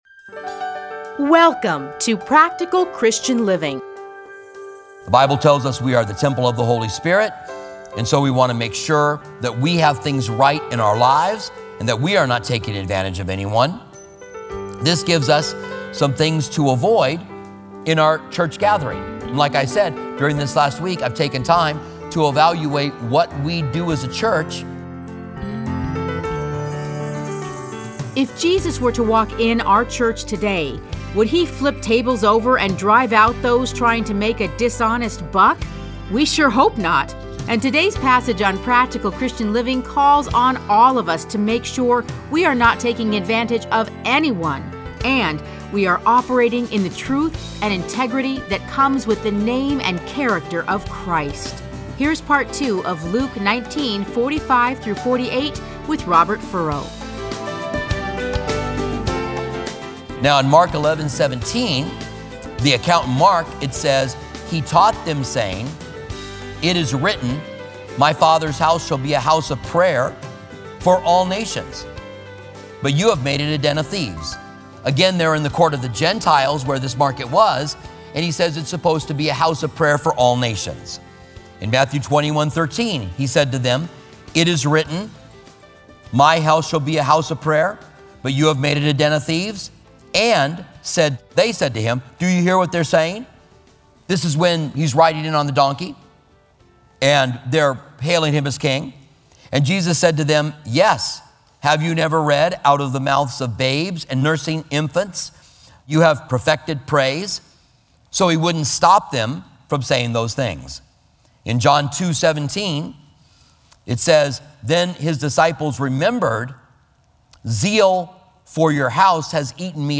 Listen to a teaching from Luke 19:45-48.